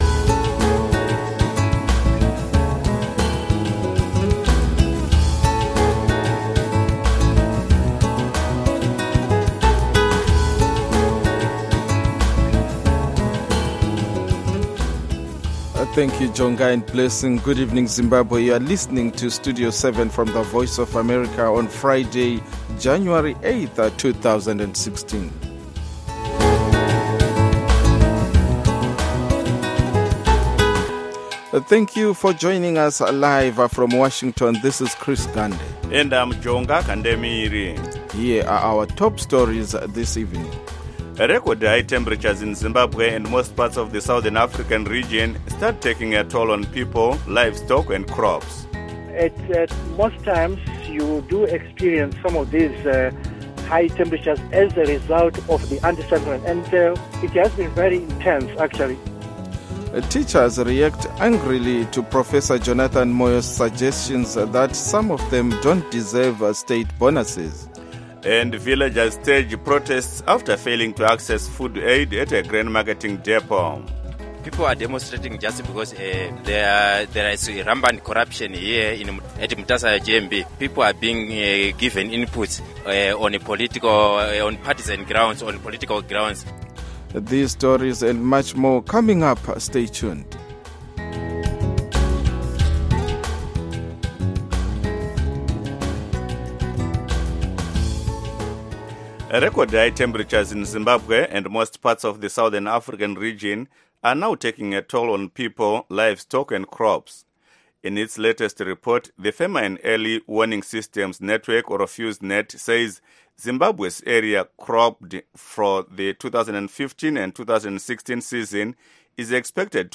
Studio 7 has been providing Zimbabwe with objective, reliable and balanced radio news broadcasts since 2003 and has become a highly valued alternative point of reference on the airwaves for many thousands of Zimbabweans. Studio 7 covers politics, civil society, the economy, health, sports, music, the arts and other aspects of life in Zimbabwe.